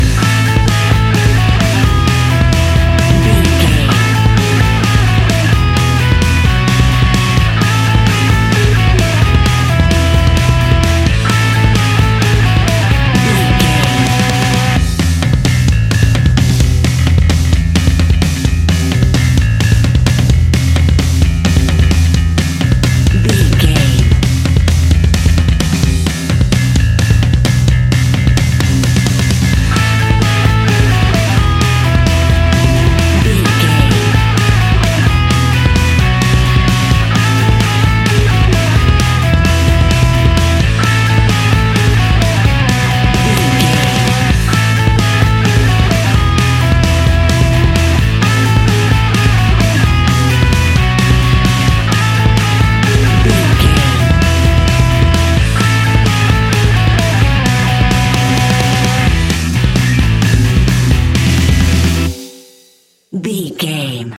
Ionian/Major
energetic
driving
heavy
aggressive
electric guitar
bass guitar
drums
hard rock
distortion
instrumentals
distorted guitars
hammond organ